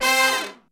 C4 POP FAL.wav